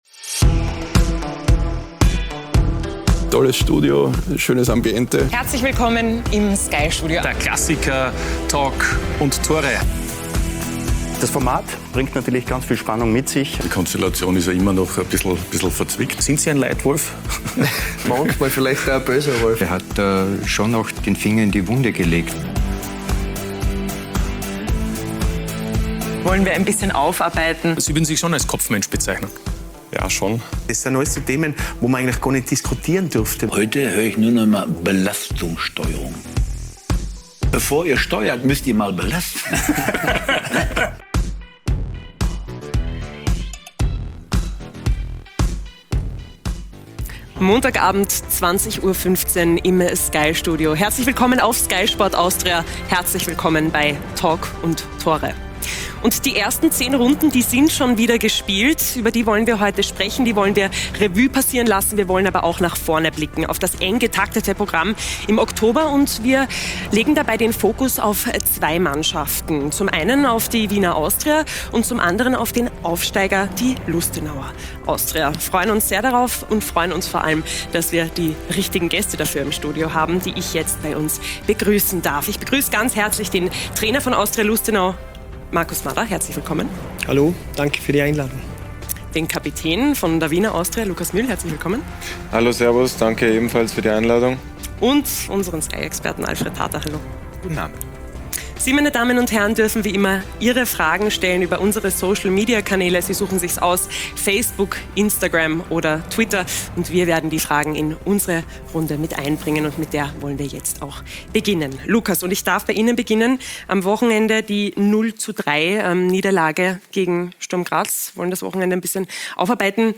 „Talk und Tore“ ist die erste und einzige Fußballtalksendung in Österreich. Wir liefern neue Blickwinkel, Meinungen und Hintergründe zu den aktuellen Themen im österreichischen Fußball und diskutieren mit kompetenten Gästen die aktuellen Entwicklungen.